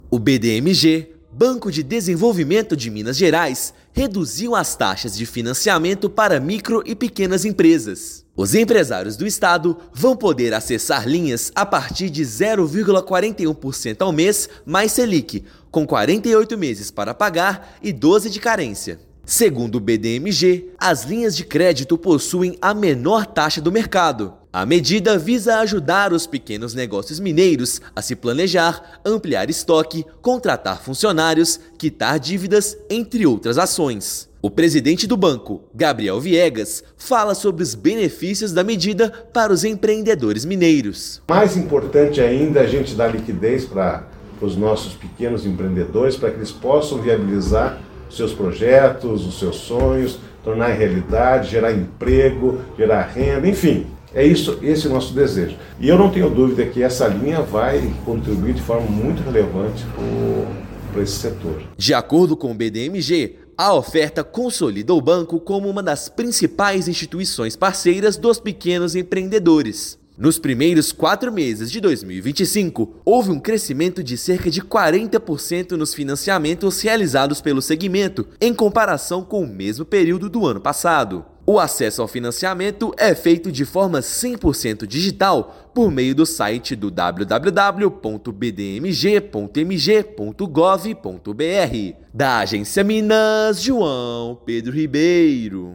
Com menor taxa do mercado, iniciativa objetiva apoiar pequenos negócios a ampliar estoque, quitar dívidas e contratar funcionários. Ouça matéria de rádio.